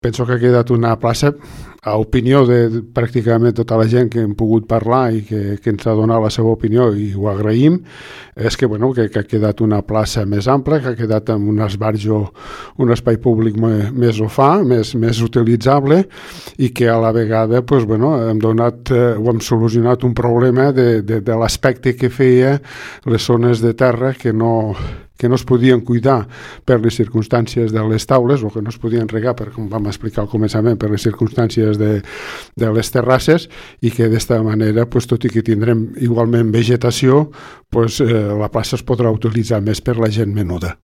Antonio Espuny és el regidor d’Obres i Serveis: